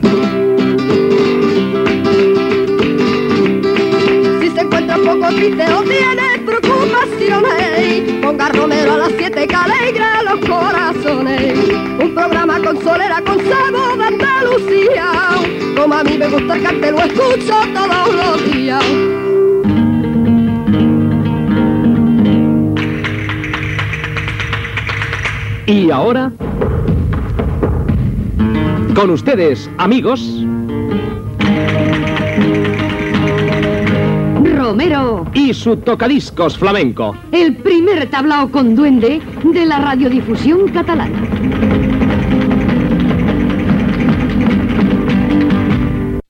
Careta del programa.
Musical